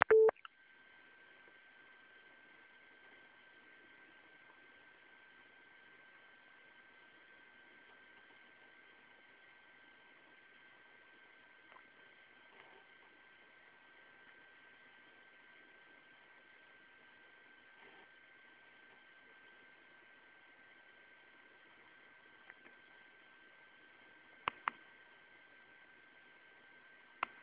Ch�odzenie standardowe - g�o�ne.